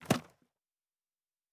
pgs/Assets/Audio/Fantasy Interface Sounds/Book 10.wav at master